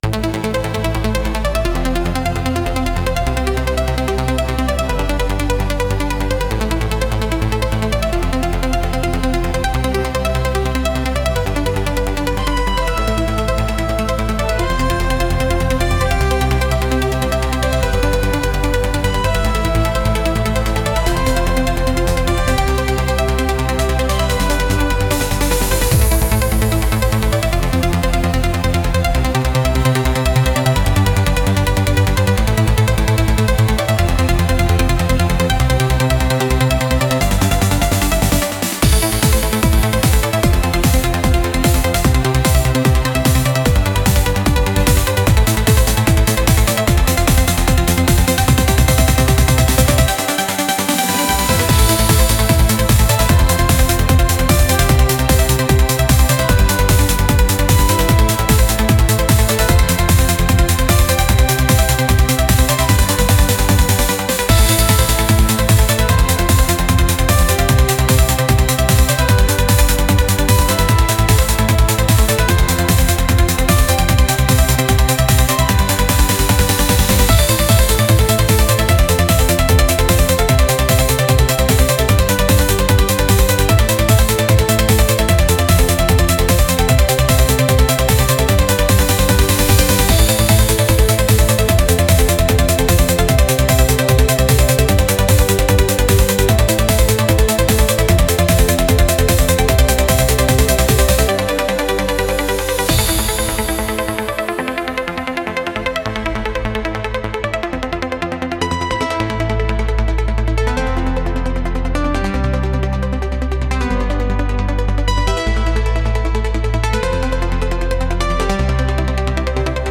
Genre: Trance Mood: Upbeat Editor's Choice